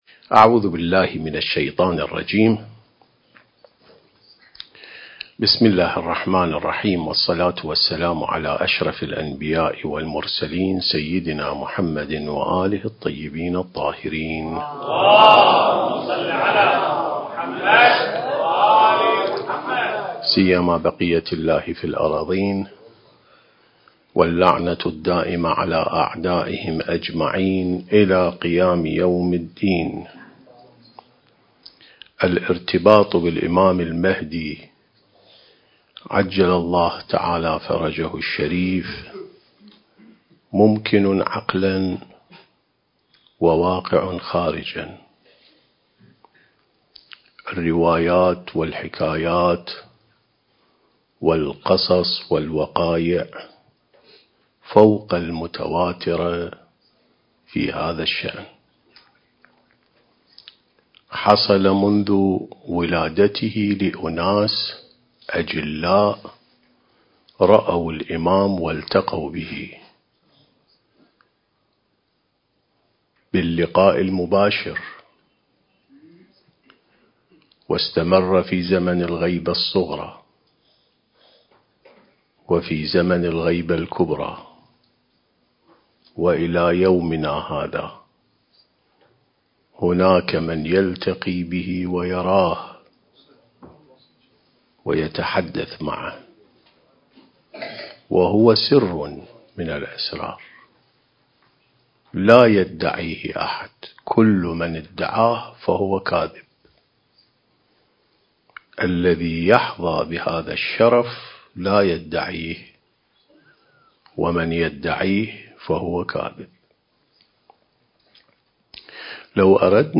عين السماء ونهج الأنبياء سلسلة محاضرات: الارتباط بالإمام المهدي (عجّل الله فرجه)/ (4)